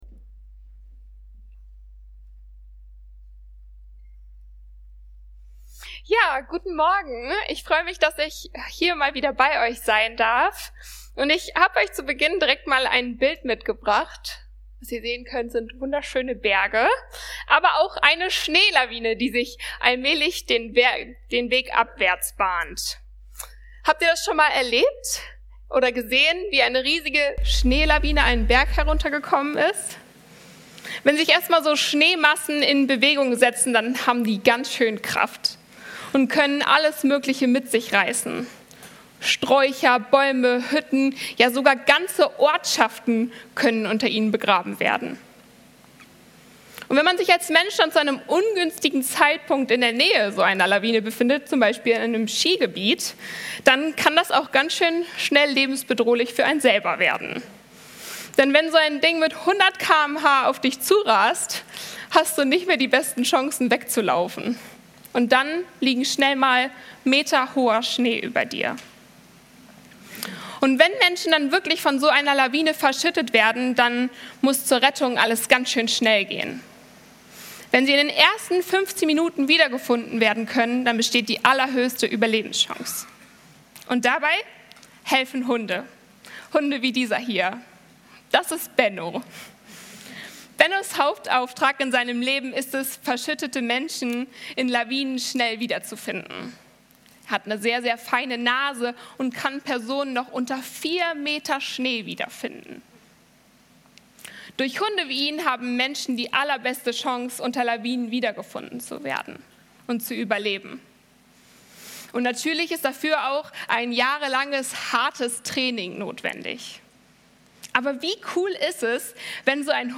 Von vorne Schaaf, von hinten Hirte - FCG-Oldenburg Predigt-Podcast